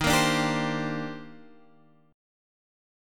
EbM13 chord